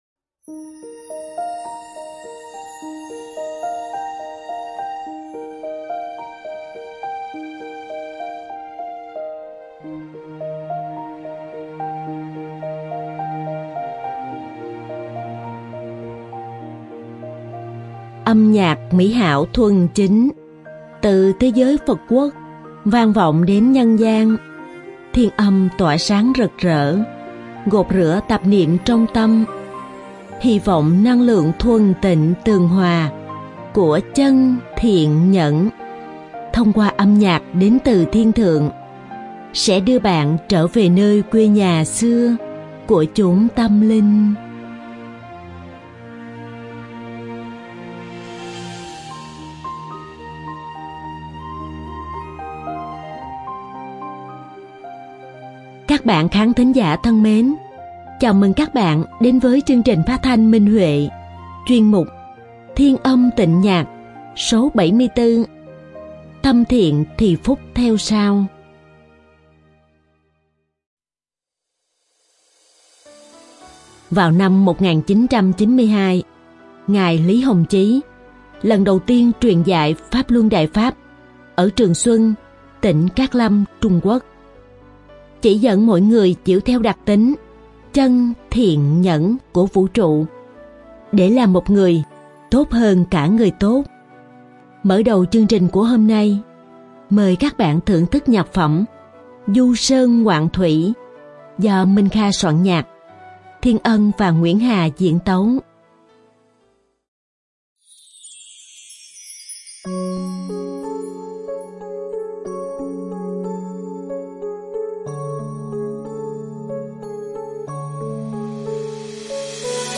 Đơn ca nam
Đơn ca nữ: Giữa thiện và ác Lời bài hát: Một niệm giữa thiện và ác, [đó là] hai tầng trời sáng tỏ và âm ám.